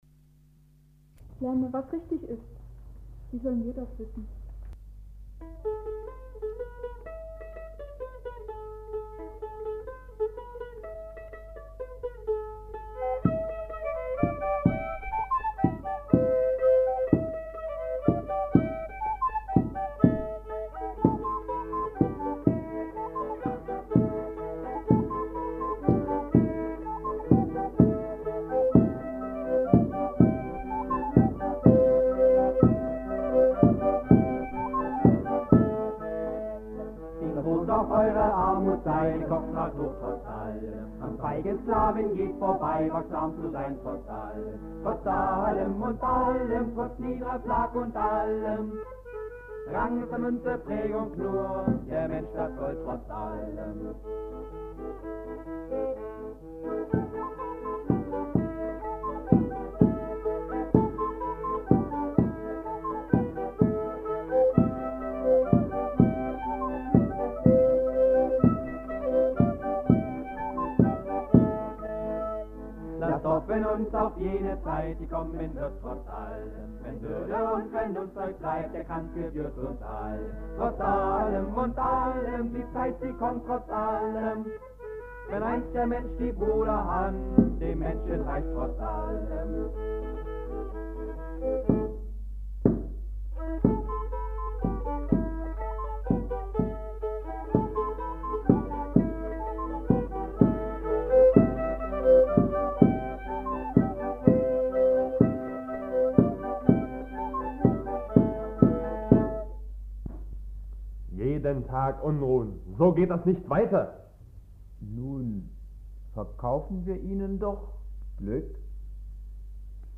Gesang, Akkordeon, Flöte
Text Klavier, Bassgitarre
Sprecher, Percussion